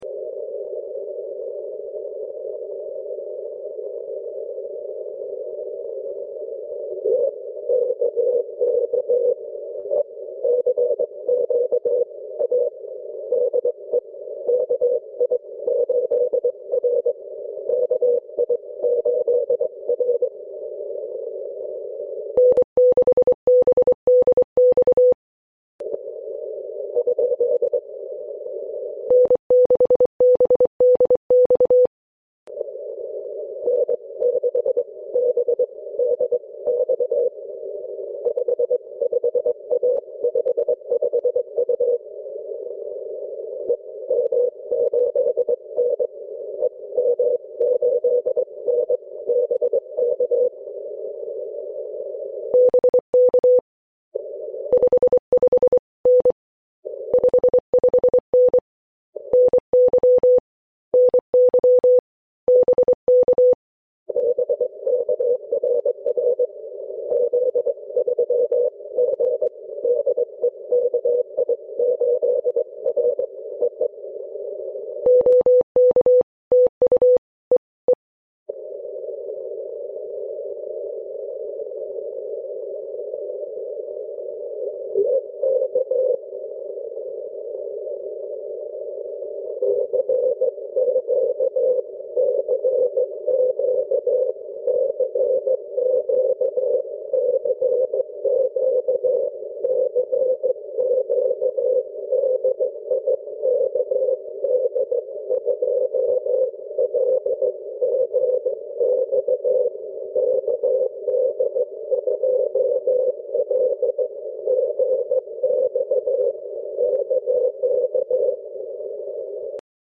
Note well that the received Morse Code signals were heavily modulated by noise presumably due to rapid random move of aurora, while they were presumably transmitted as pure tones.